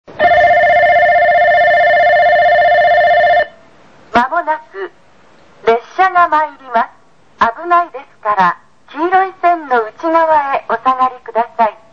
◆ 2002年2月以前の旧放送
スピーカー：TOAホーン中
接近放送・女性　(59KB/11秒) 汎用型A(低速) CMT
放送・放送設備 旧放送は「汎用型A」の低速ヴァージョンが使用されていた。